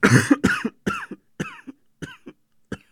cough_1.ogg